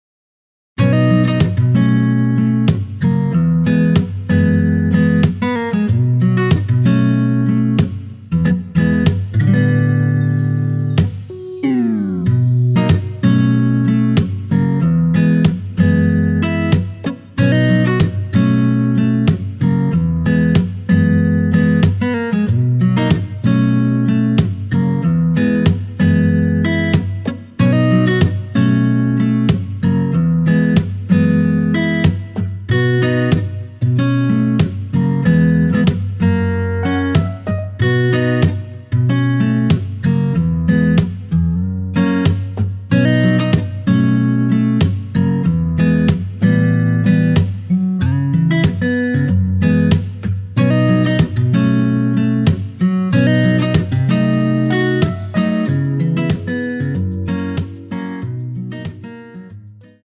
(-2)내린 MR 입니다.(미리듣기 참조)
앞부분30초, 뒷부분30초씩 편집해서 올려 드리고 있습니다.